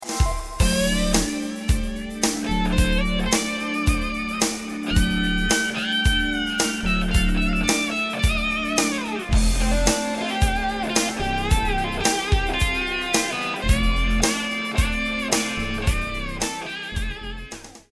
intense category-defying instrumentals